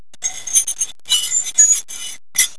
Kratzen mit einer Gabel auf der Rückseite einer Fliese,
ziegeltopf-umkopiert-a.wav